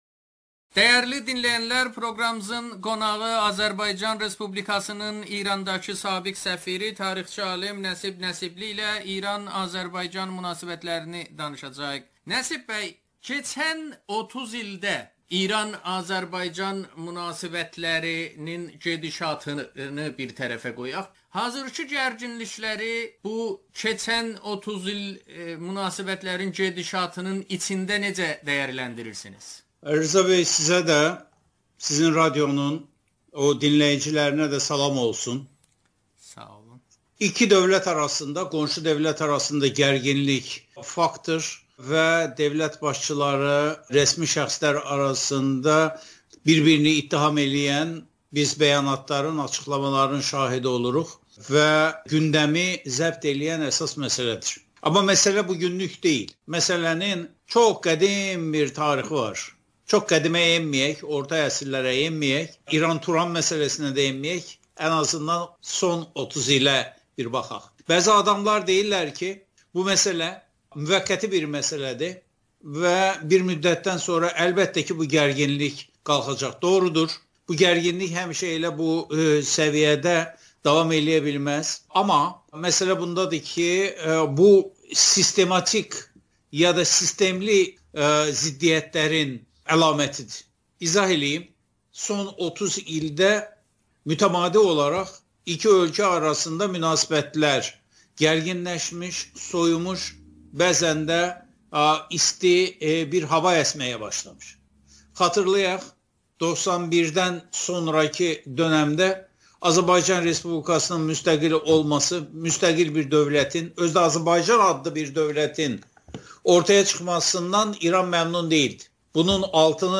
Nəsib Nəsibli ilə müsahibə